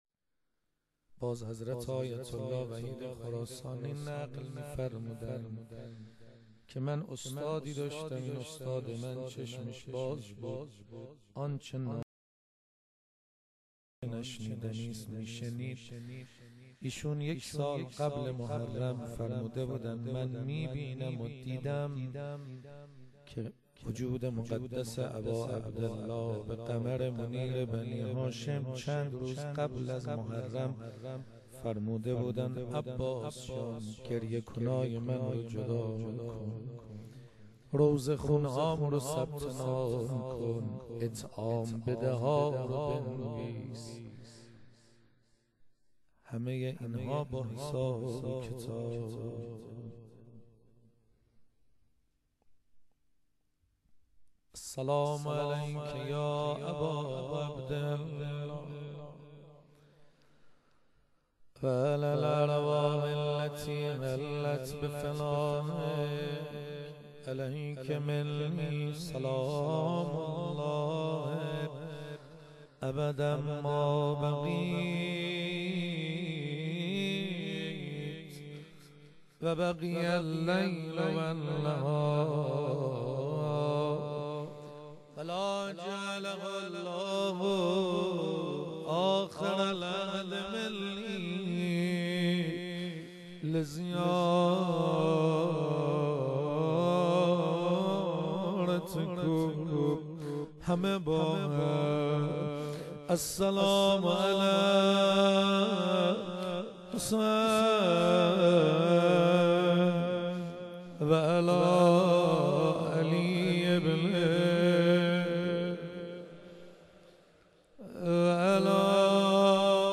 روضه حضرت ابالفضل(ع)- قزوین-آستان مقدس چهارانبیا-موسسه پرچمدار.mp3